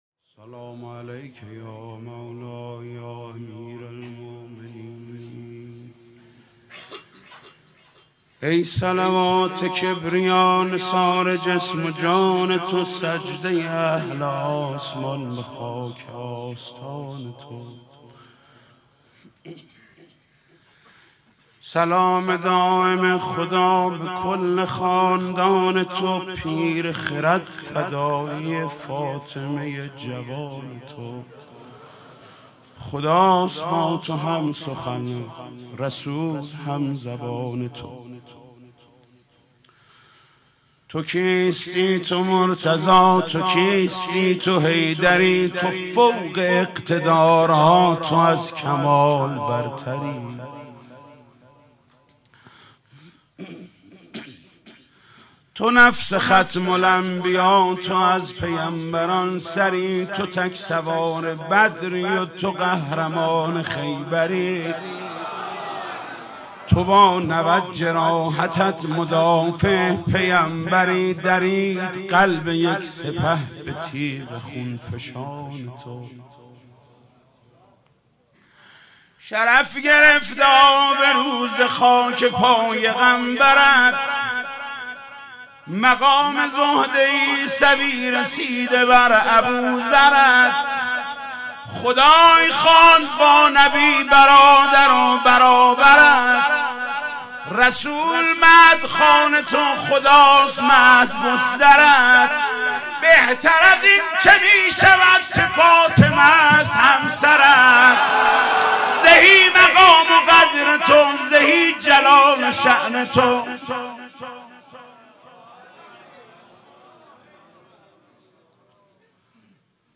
مداحی و نوحه
روضه خوانی در شهادت حضرت فاطمه زهرا(س)